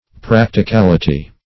Practicality \Prac`ti*cal"i*ty\, n.
practicality.mp3